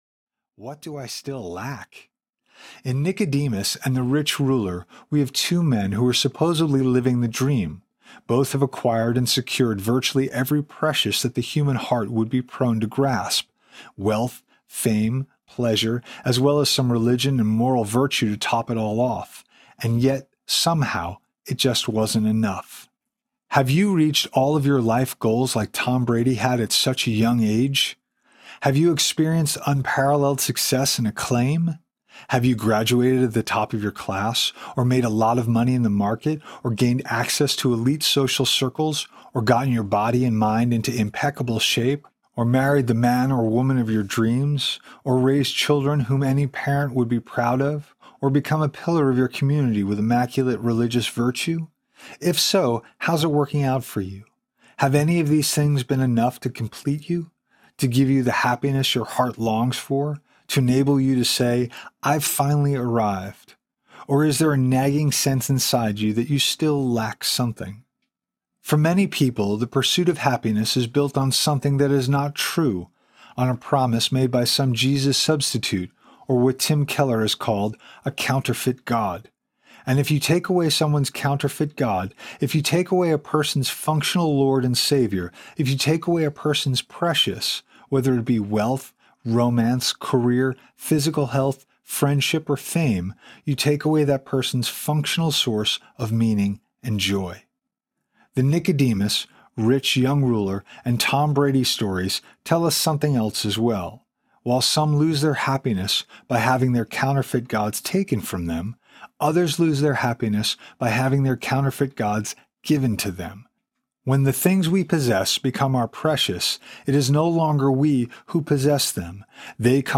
Irresistible Faith Audiobook
7.27 Hrs. – Unabridged